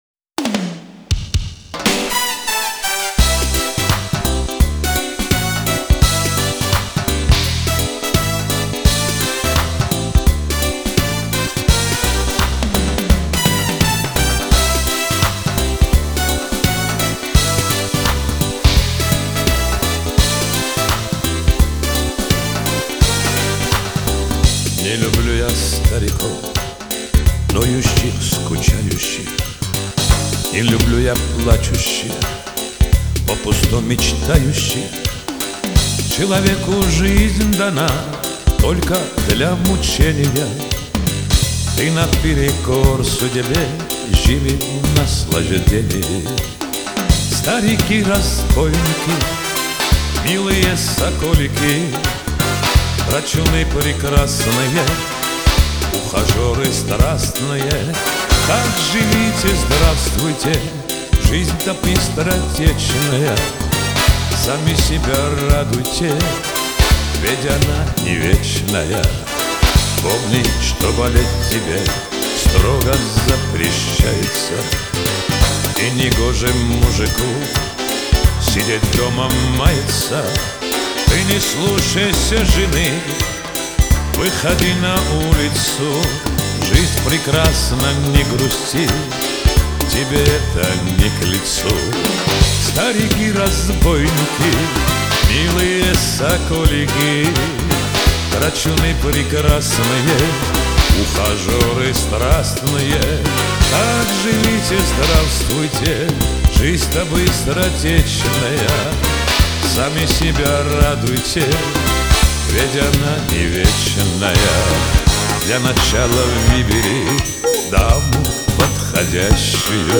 Лирика
Шансон